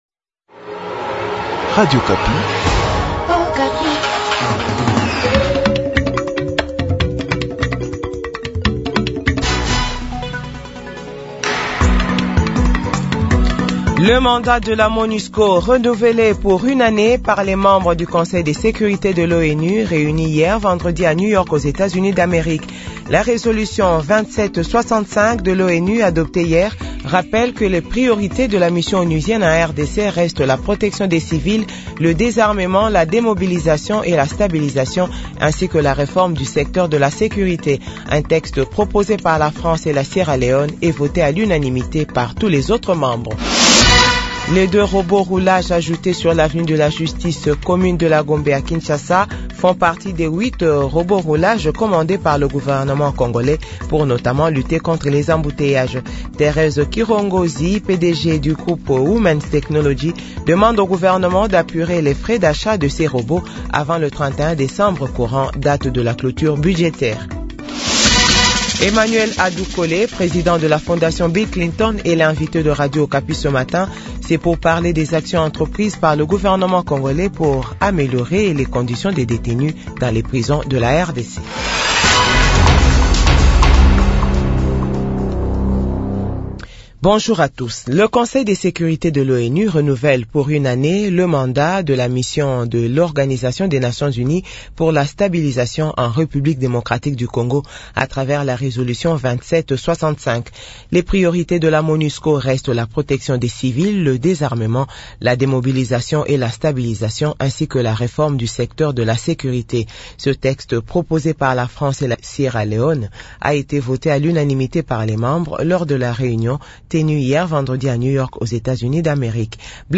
JOURNAL FRANÇAIS DE 8H00